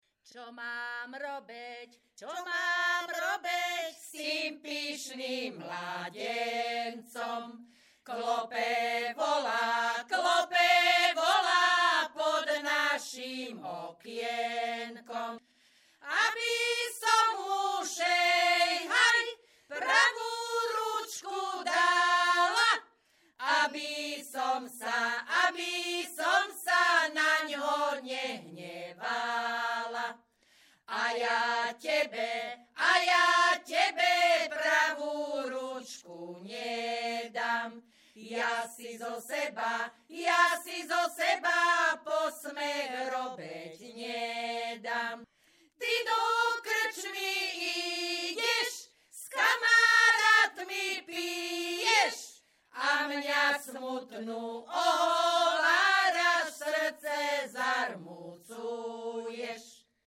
Descripton ženský skupinový spev bez hudobného sprievodu
Performers Spevácka skupina Hronka z Brehov
Place of capture Brehy
Key words ľudová pieseň